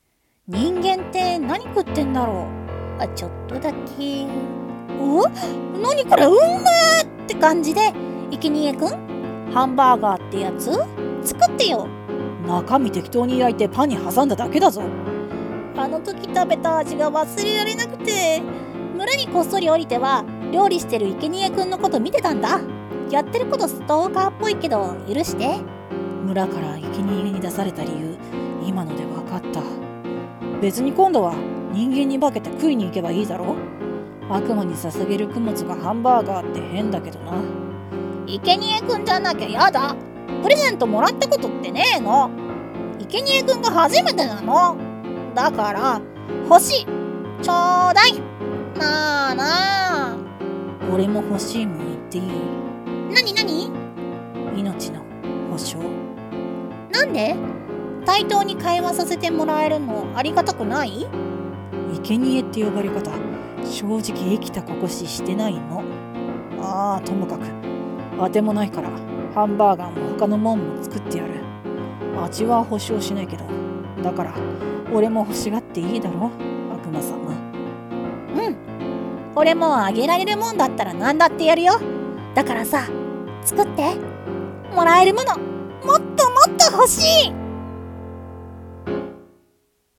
声劇台本「平和主義的悪魔の欲求」